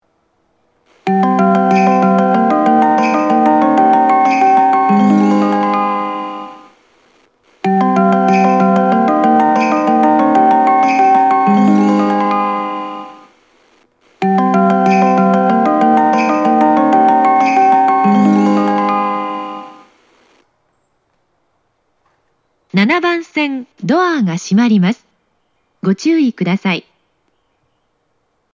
発車メロディー
3コーラスです!全列車が始発で曲が短いので、複数コーラス余裕で収録できます。